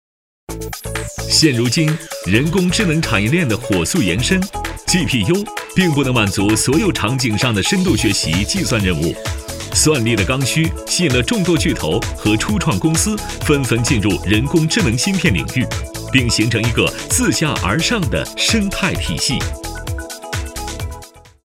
宣传片配音